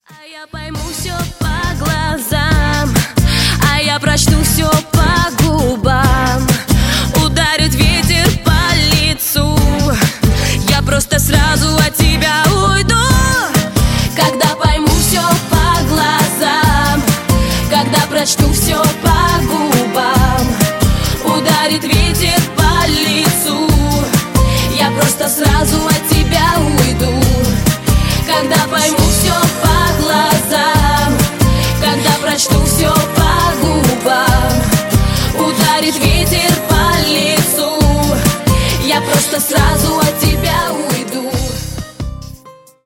• Качество: 128, Stereo
поп
женский вокал